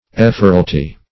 Ephoralty \Eph"or*al*ty\, n. The office of an ephor, or the body of ephors.